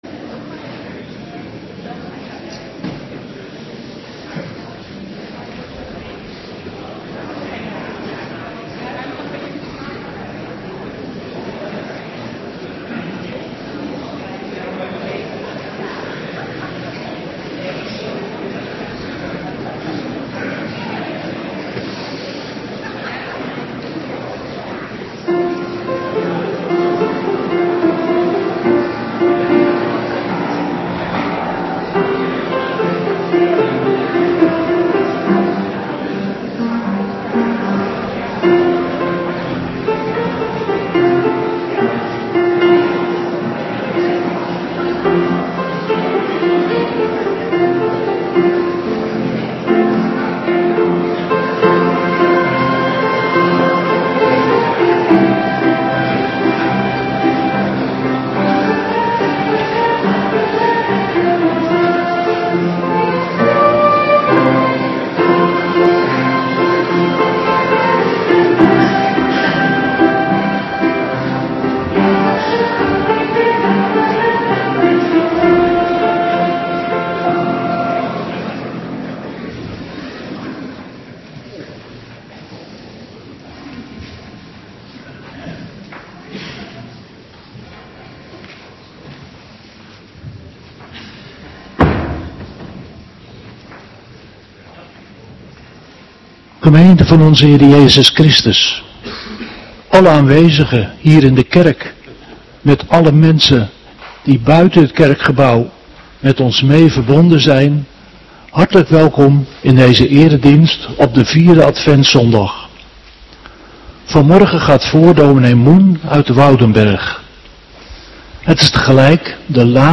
Morgendienst 21 december 2025